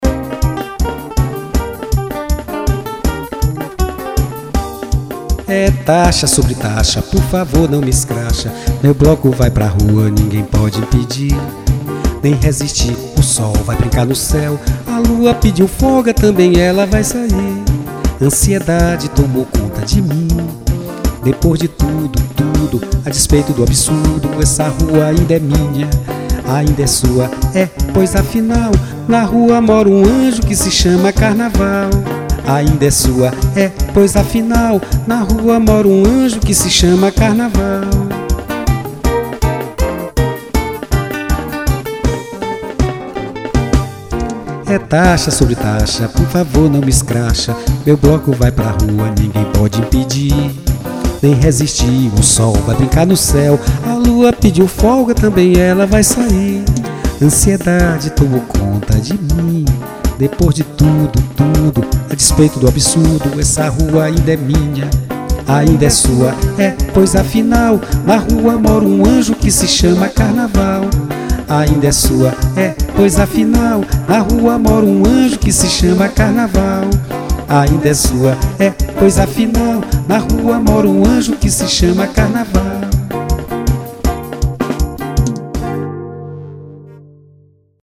Frevo